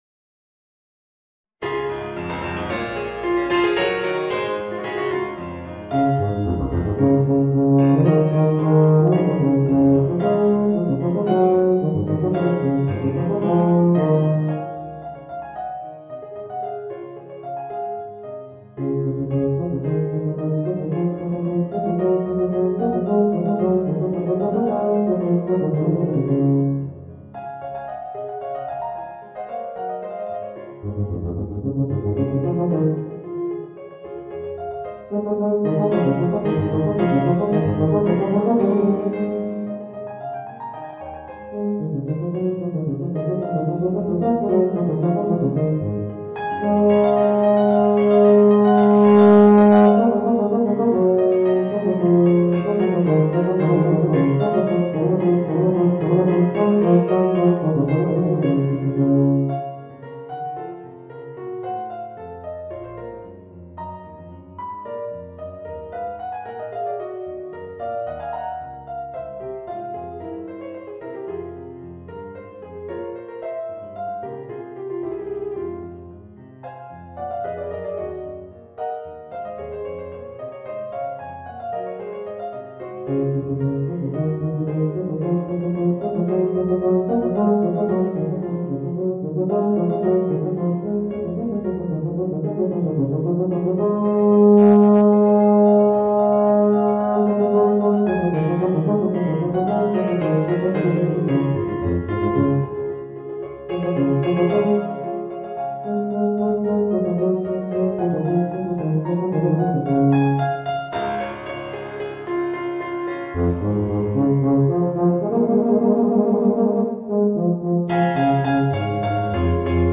Voicing: Eb Bass and Piano